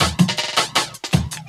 12 LOOP11 -R.wav